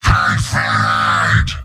Giant Robot lines from MvM. This is an audio clip from the game Team Fortress 2 .
Heavy_mvm_m_thanksfortheteleporter01.mp3